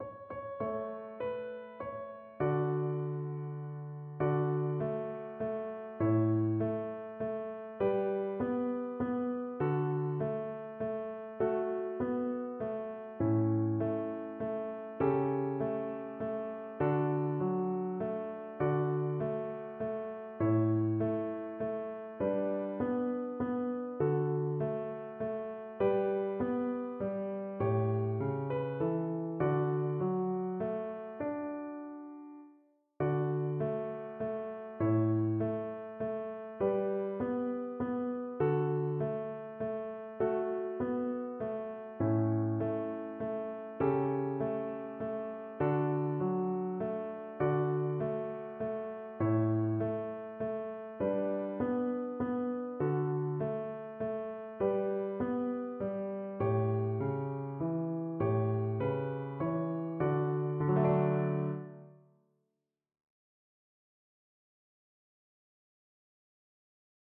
Violin
"Bahay Kubo" is a traditional Filipino folk song. It tells of a small hut (kubo in Tagalog) made out of nipa palm leaves, with the variety of vegetables surrounding it.
D major (Sounding Pitch) (View more D major Music for Violin )
3/4 (View more 3/4 Music)
F#5-F#6
Traditional (View more Traditional Violin Music)